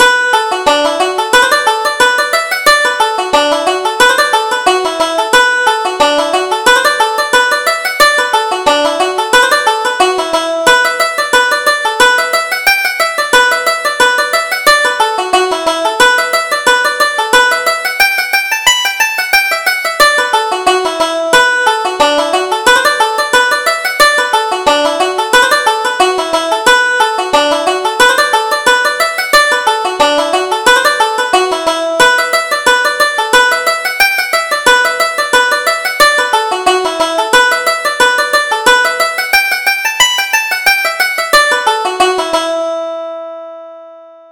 Reel: Lovely Molly